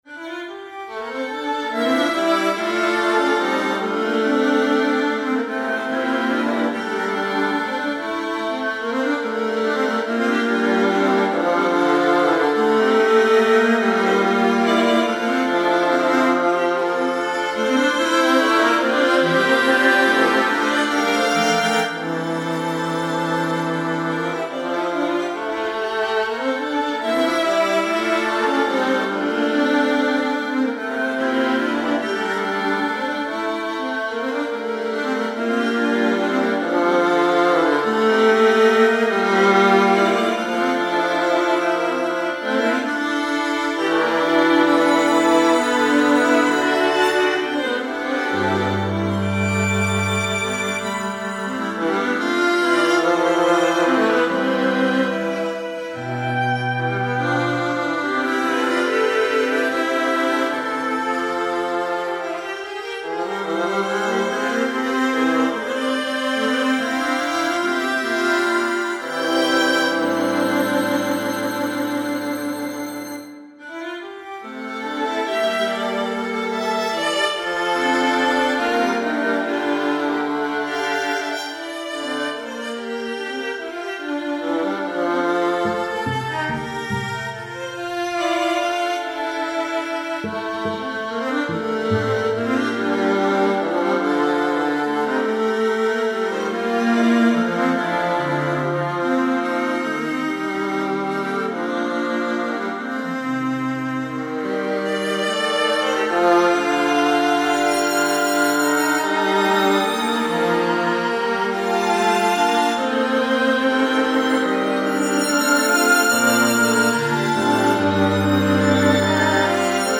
Midi Recording -
Rhapsody No.1 for String Sextet.mp3